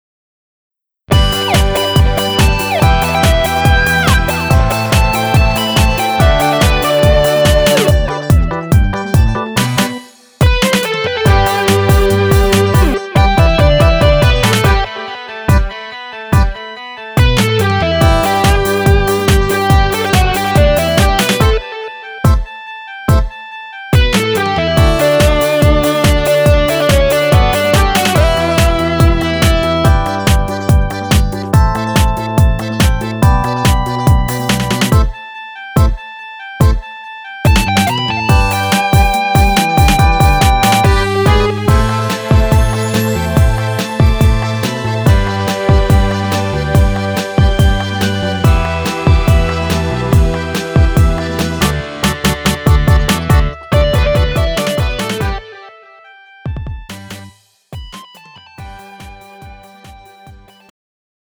음정 여자키 3:05
장르 가요 구분 Pro MR